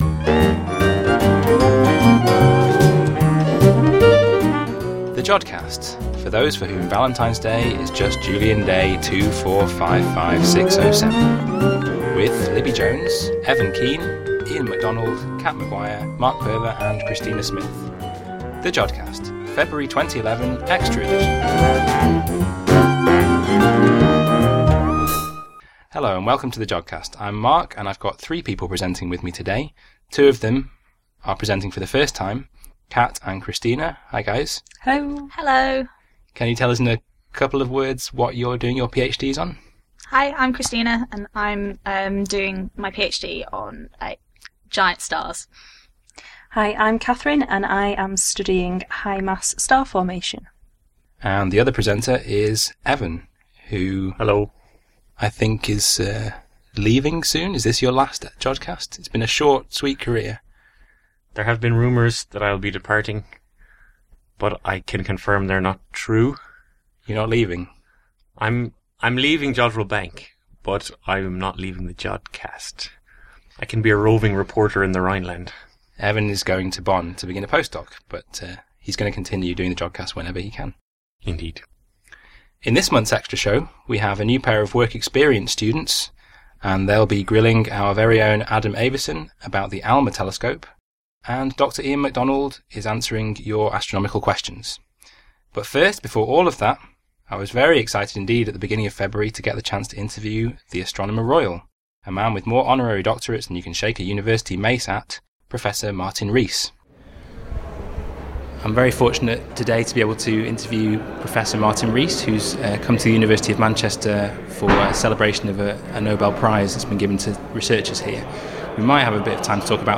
Interview with Professor Martin Rees